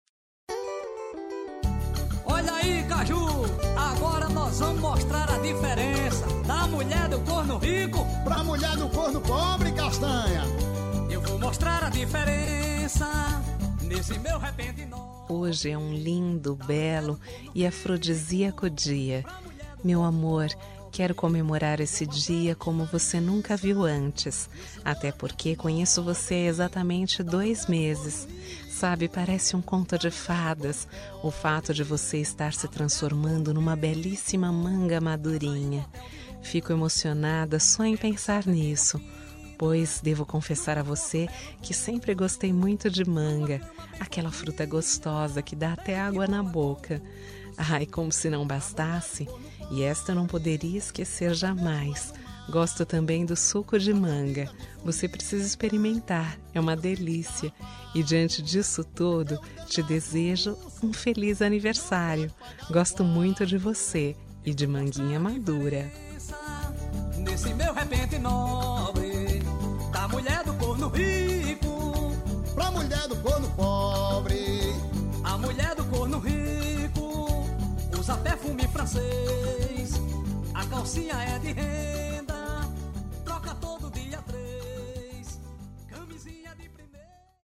Aniversário de Humor – Voz Feminina – Cód: 200113